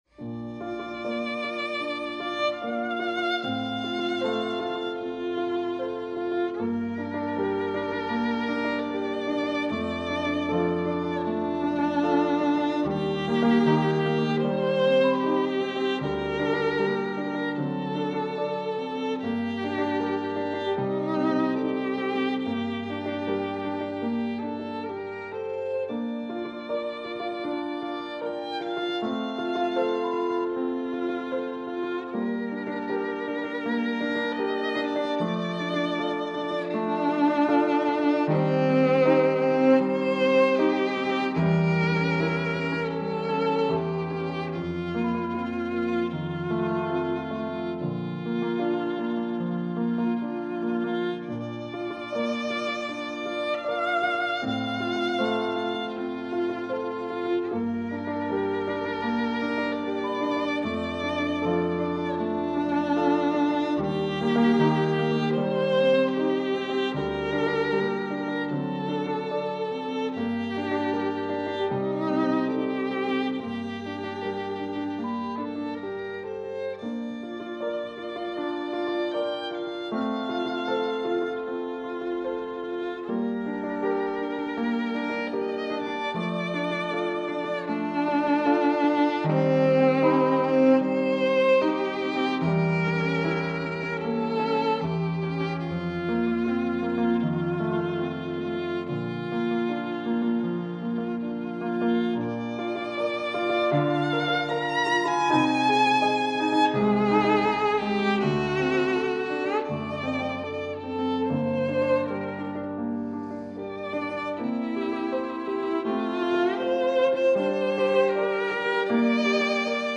Recueil pour Violon